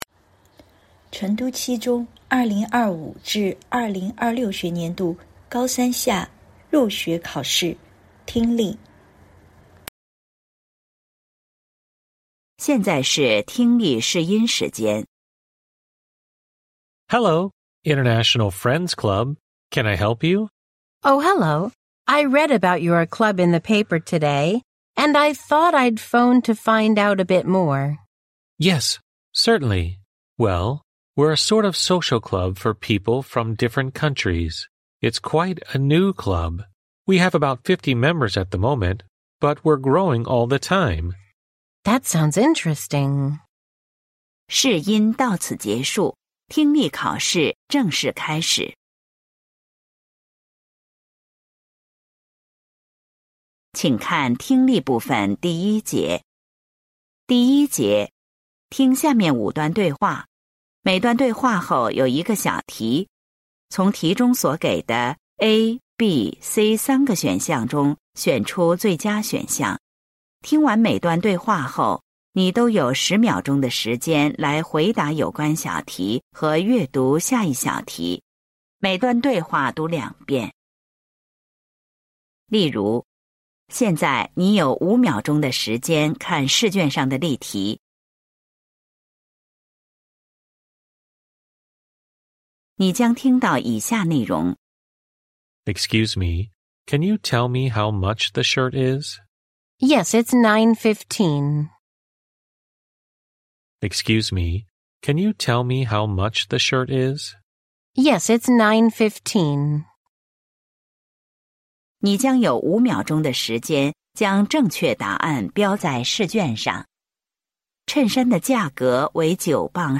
成都七中2026届高三下学期开学考英语听力.mp3